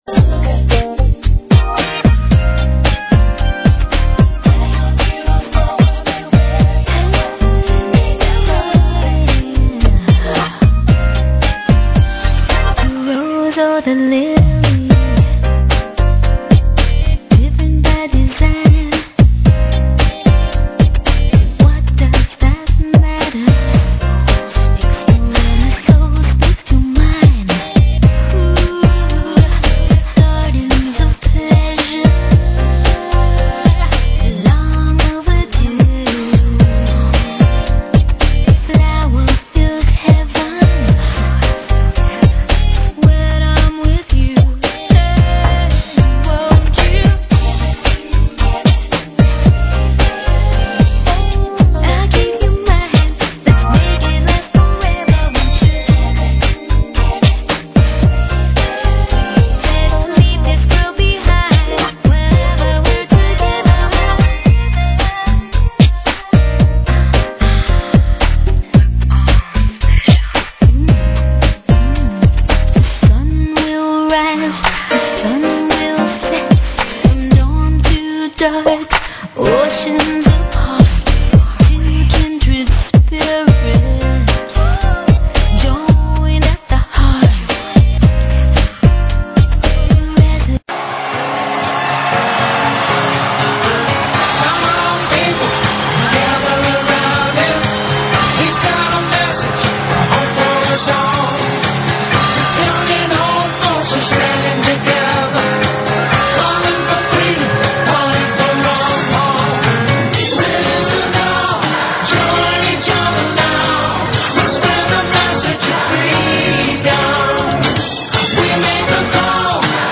Talk Show Episode, Audio Podcast, The_Freedom_Message and Courtesy of BBS Radio on , show guests , about , categorized as
It's a live internet radio call in show for and about the Ron Paul Revolution.